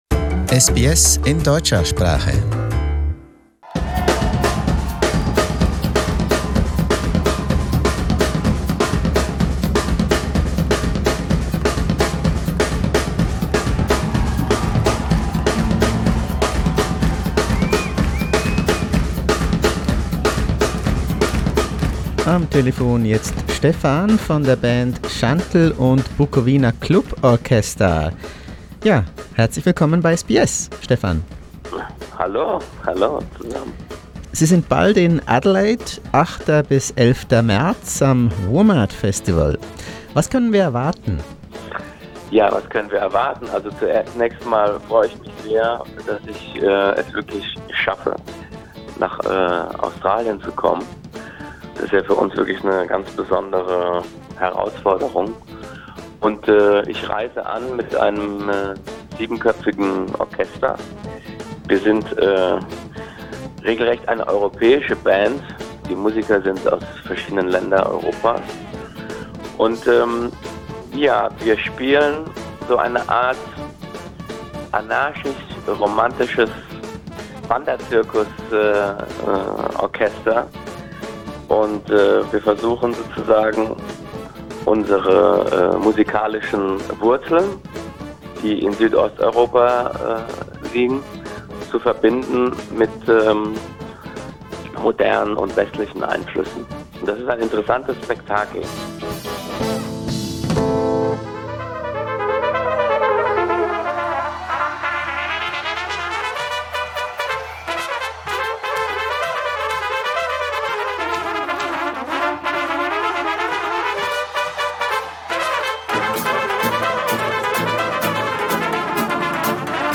Now he's coming to Australia for two performances at WOMAD- the World Music Festival in Adelaide. We asked Shantel to tell us more about his unique style.